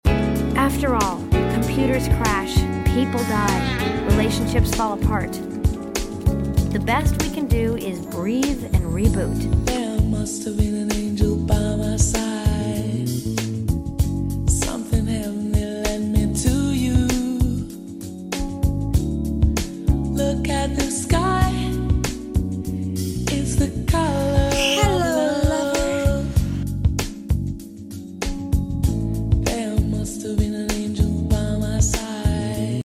Idk why but this sound is so relaxing